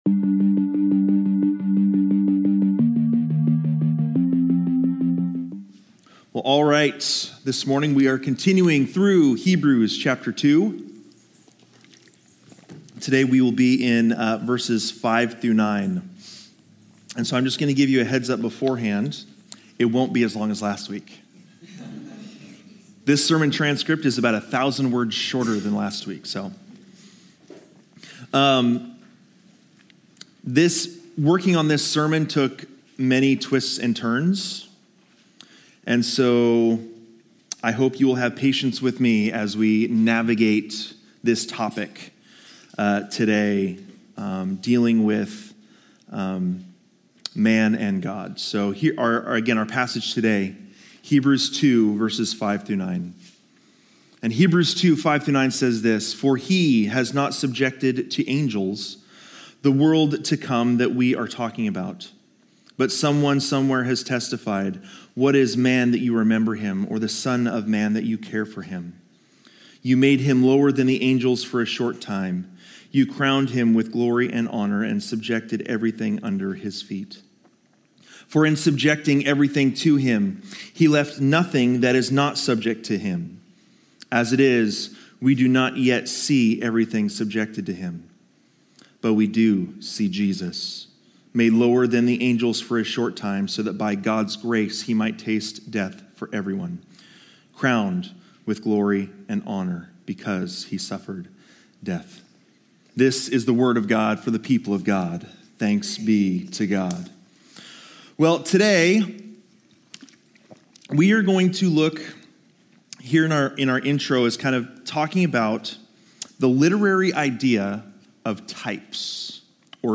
Sermons | Living Word Community Church